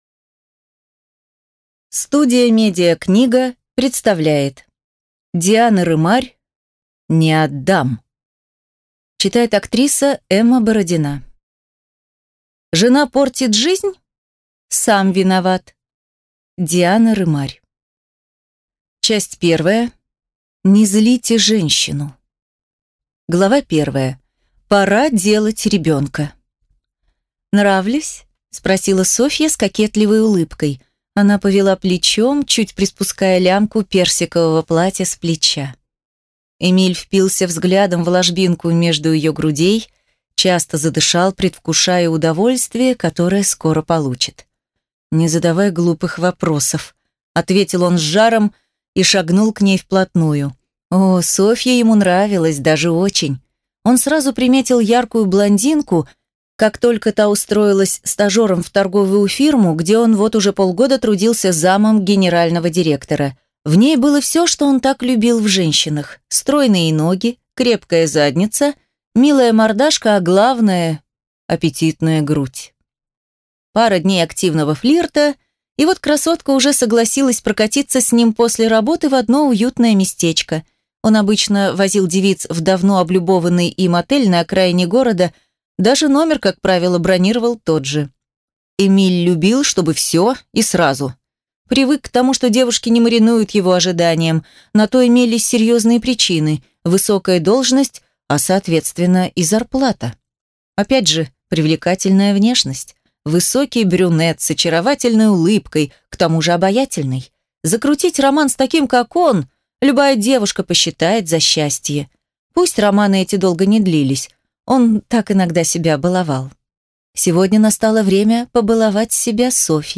Aудиокнига Не отдам